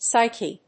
音節Psy・che 発音記号・読み方
/sάɪki(米国英語), ˈsaɪki:(英国英語)/